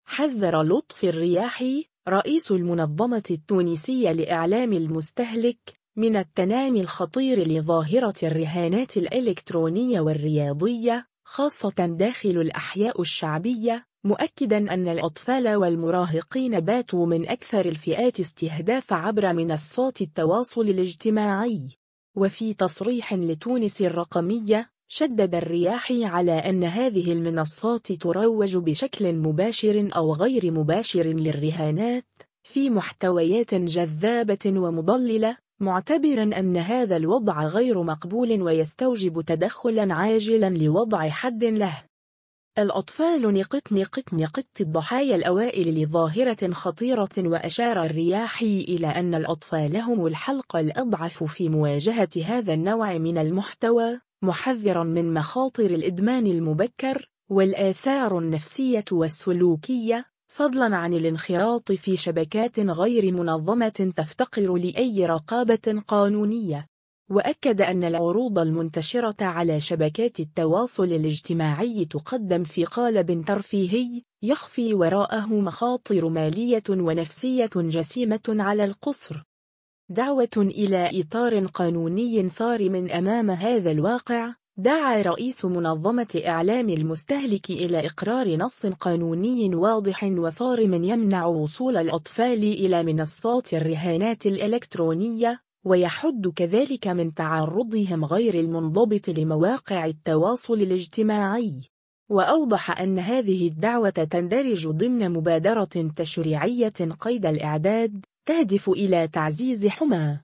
وفي تصريح لتونس الرقمية